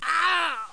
GRUNT1.mp3